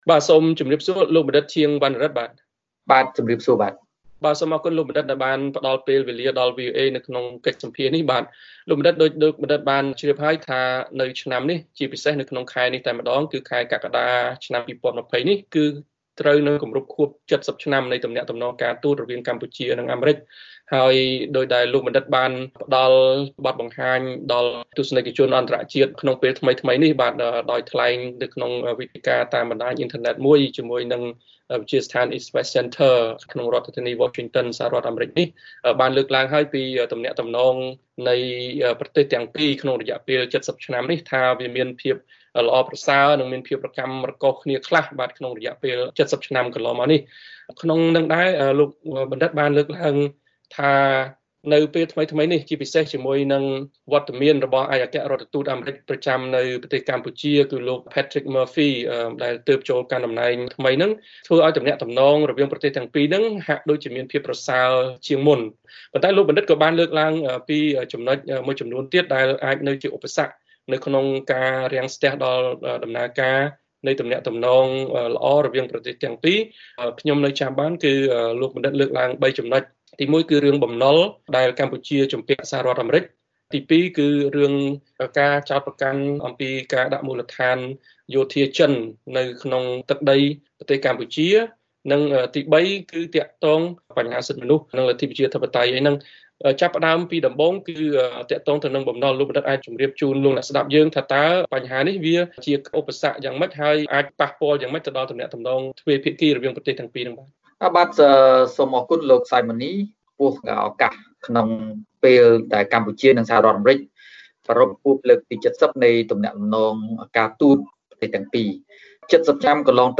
បទសម្ភាសន៍ VOA ៖ អ្នកវិភាគថា ស.រ.អា មិនចង់ឃើញការផ្លាស់ប្តូររបបនៅកម្ពុជា គឺជាប័ណ្ណធានារ៉ាប់រងនយោបាយសម្រាប់កម្ពុជា